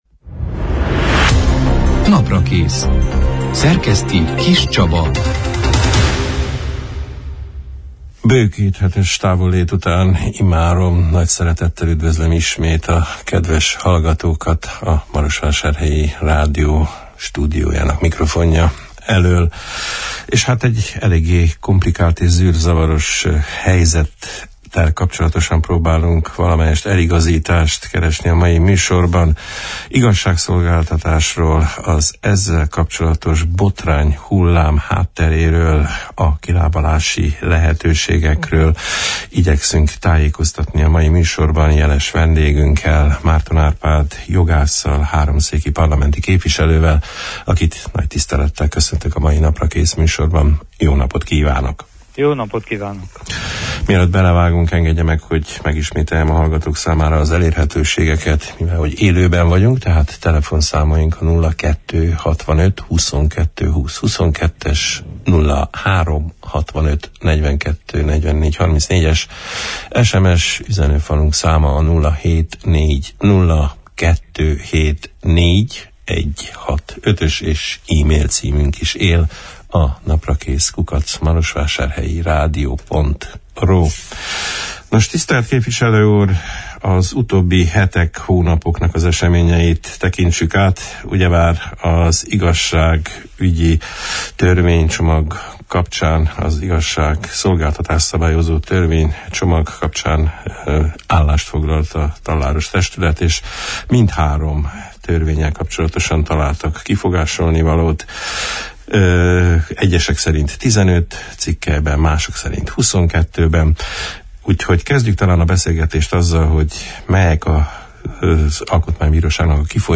Az igazságügyi törvények kapcsán az Alkotmánybíróság által hozott döntések részleteiről, azok újratárgyalásának és a törvénycsomagba történő beépítésének folyamatáról, az Országos Korrupcióellenes Ügyészség ( DNA ) körül kialakult botrányról, az intézmény vezetője menesztése érdekében gyakorolt nyomásról, a további fejlemények alakulásáról beszélgettünk a február 19 -én, hétfőn elhangzott Naprakész műsorban vendégünkkel, Márton Árpád jogásszal, az RMDSZ háromszéki parlamenti képviselőjével.